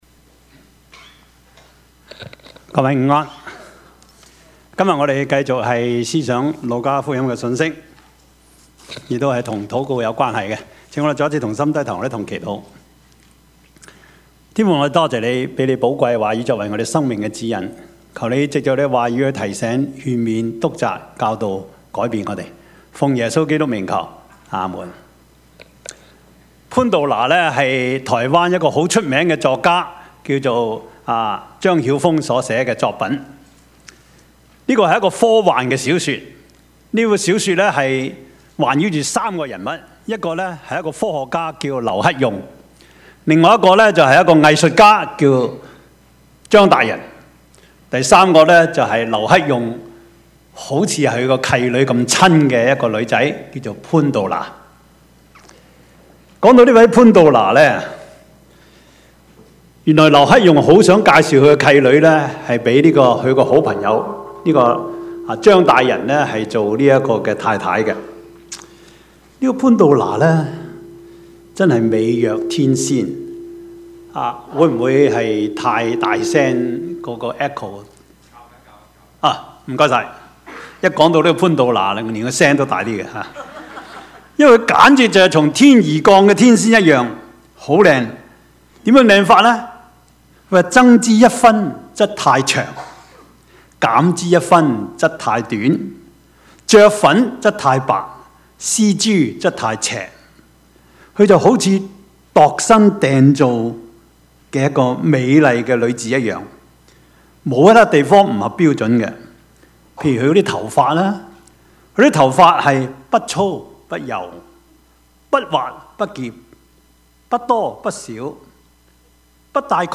Service Type: 主日崇拜
Topics: 主日證道 « 市場､試場 做人為了什麼?